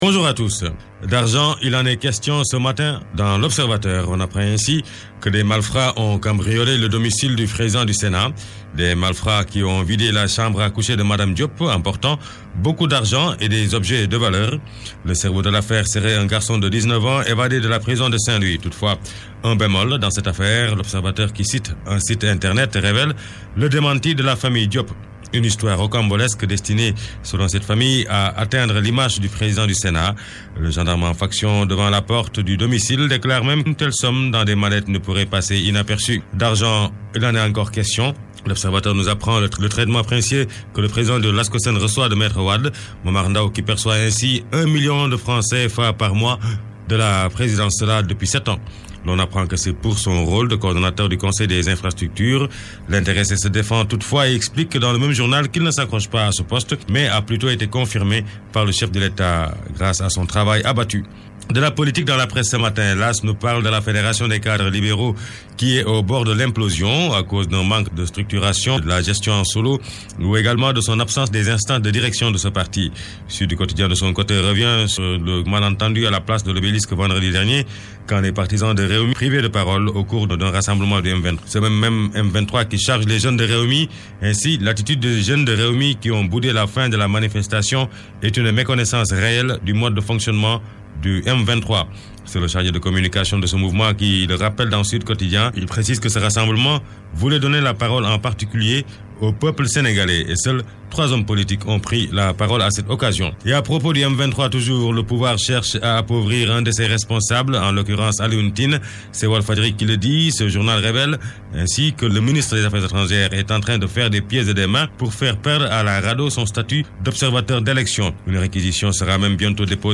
[ AUDIO ] Revue de presse RFM du 30 Septembre (Français )